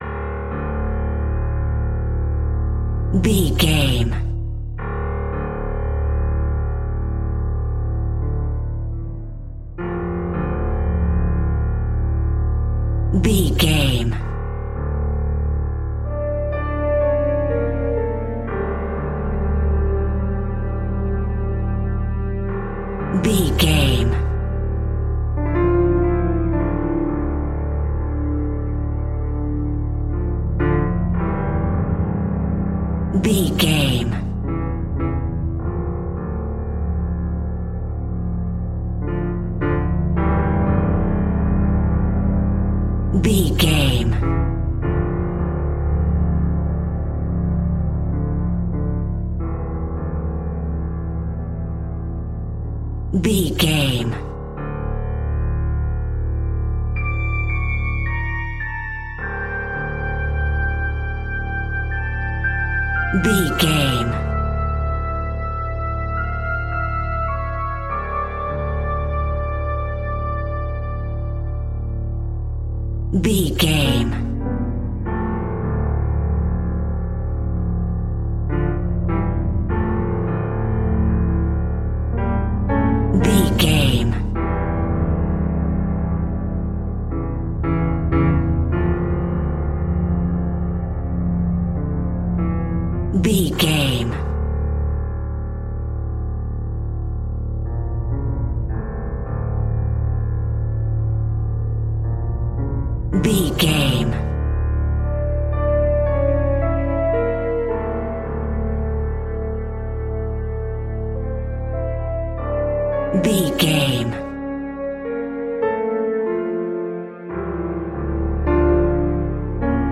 Aeolian/Minor
Slow
tension
ominous
dark
haunting
eerie
melancholic
synth
pads